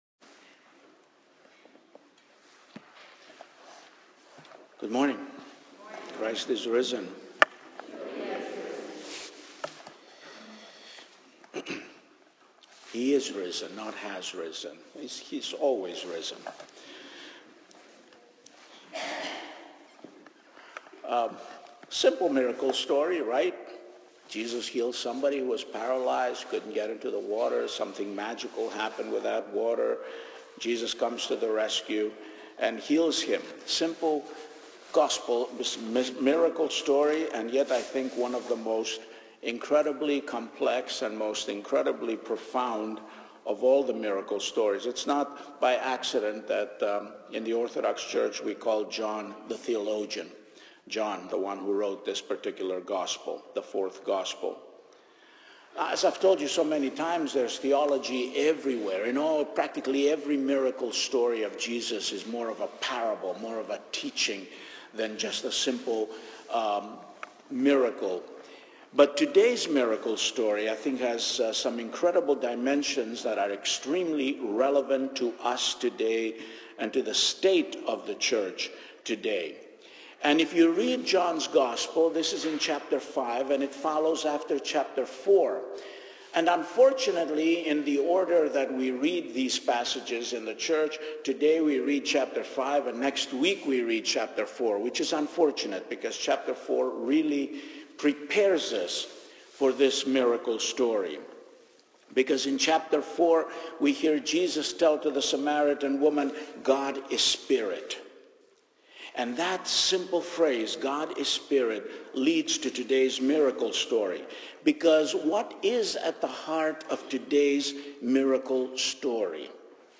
Sermon of 3 May, 2015, Audio file: